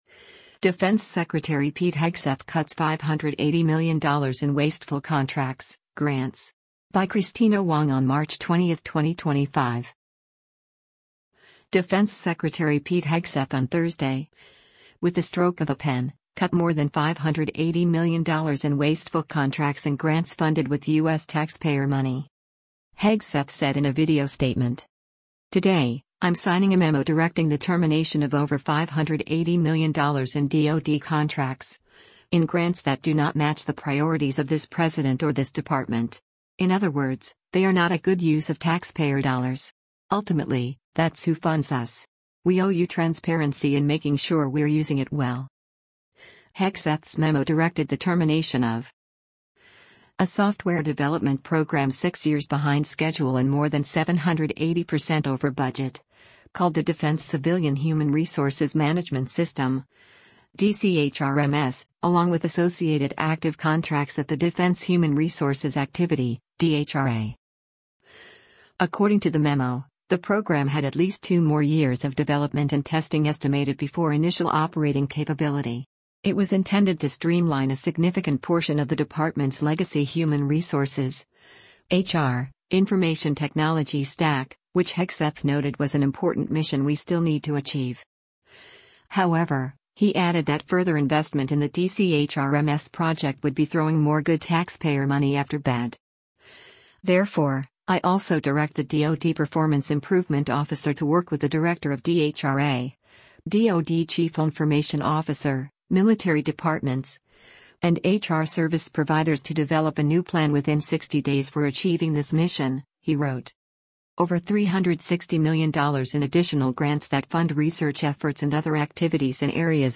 Hegseth said in a video statement: